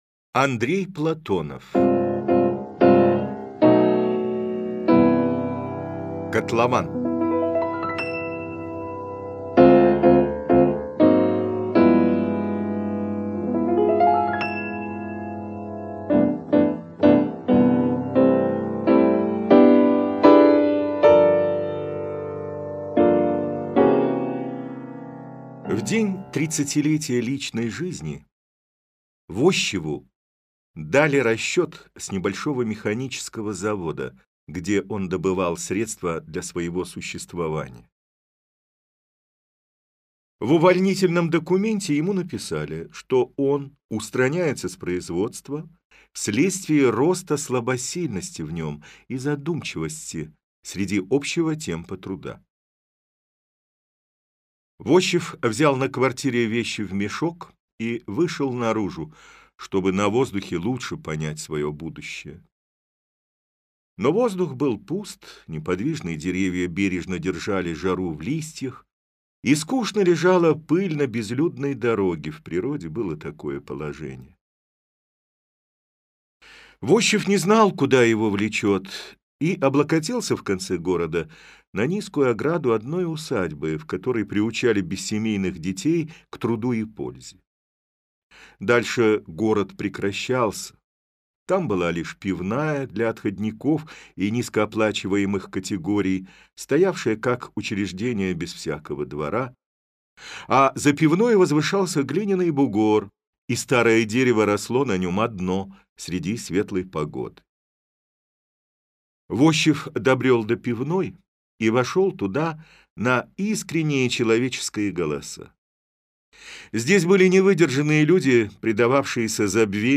Аудиокнига Котлован - купить, скачать и слушать онлайн | КнигоПоиск